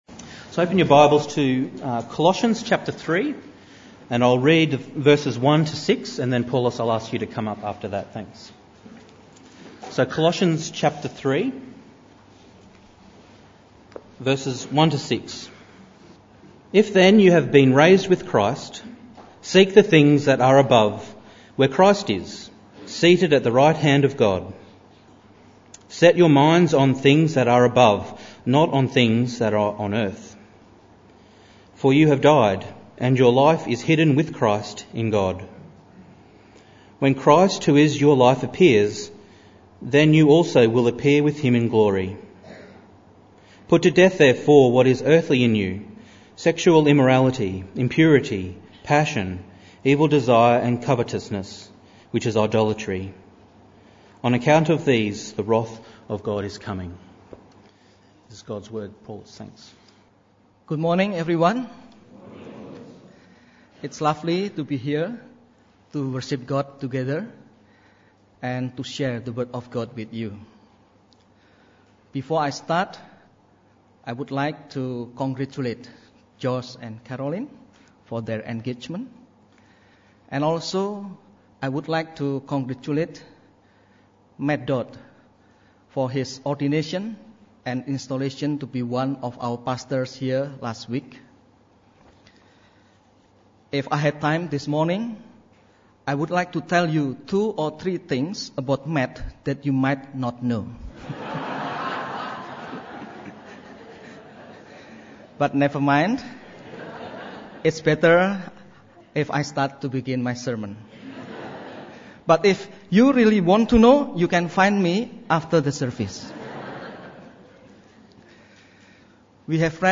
Passage: Colossians 3:1-6 Service Type: Morning Service Bible Text